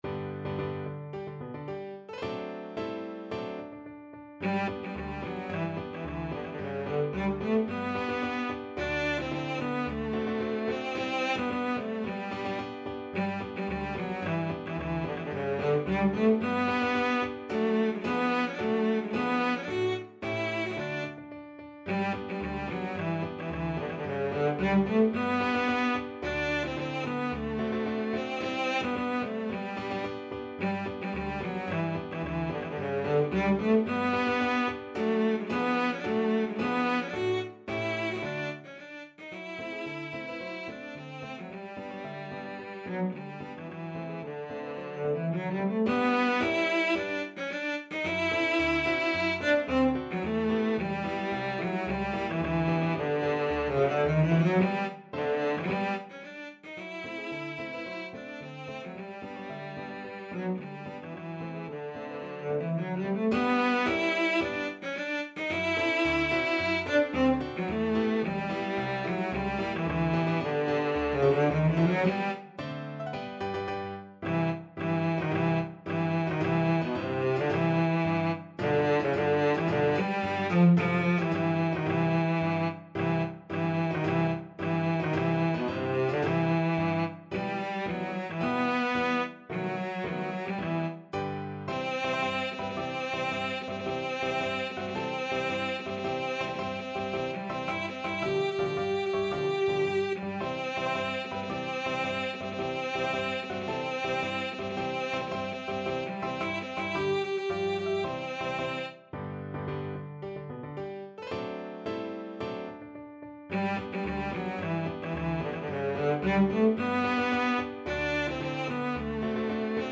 Cello
G major (Sounding Pitch) (View more G major Music for Cello )
2/2 (View more 2/2 Music)
March =c.110
Classical (View more Classical Cello Music)